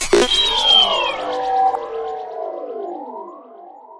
宇宙空间持续音
描述：宇宙空间持续音，科幻环境音
Tag: 科幻 持续音 质感 空间 宇宙的 合成器 FX 环境音 pad 声景